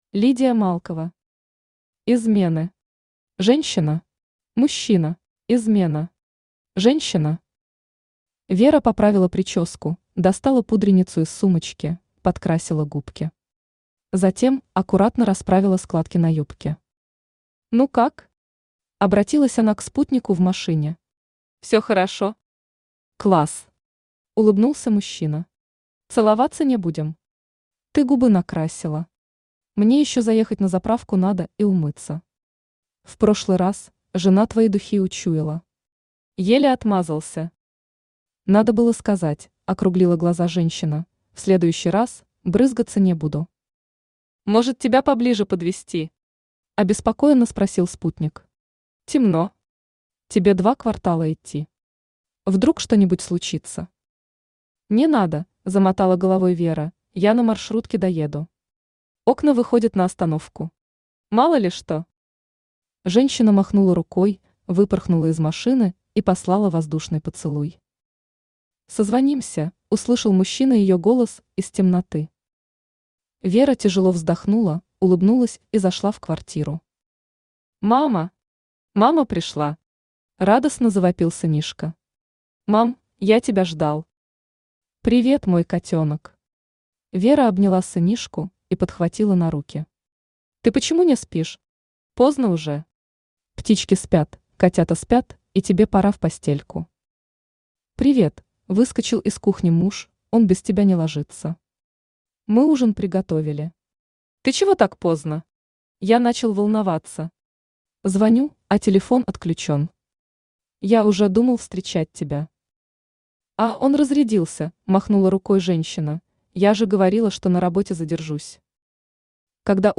Аудиокнига Измены. Женщина. Мужчина | Библиотека аудиокниг
Мужчина Автор Лидия Малкова Читает аудиокнигу Авточтец ЛитРес.